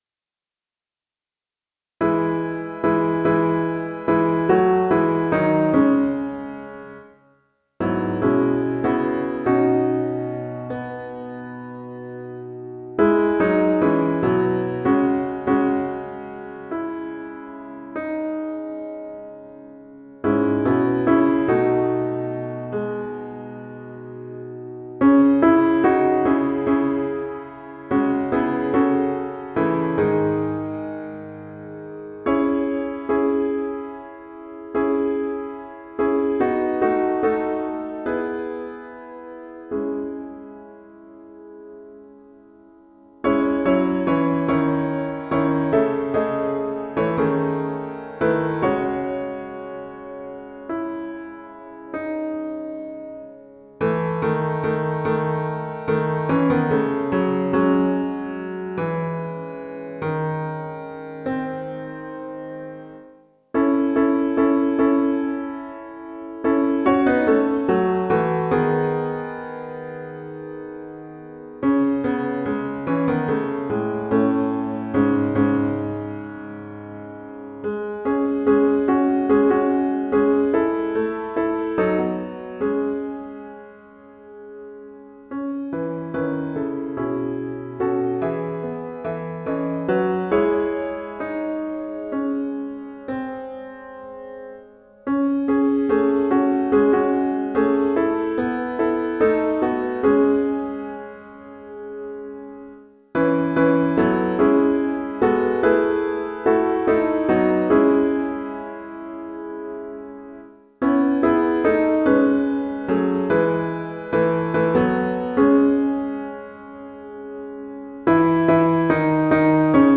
Title Sonnet 94 (SATB) Opus # 353 Year 2006 Duration 00:02:11 Self-Rating 1 Description The choral director has much leeway with this piece, especially regarding the pauses mentioned in the instructions, and variations in tempo.
This score makes a similar change. mp3 download wav download Files: wav mp3 Tags: Choral Plays: 1391 Likes: 0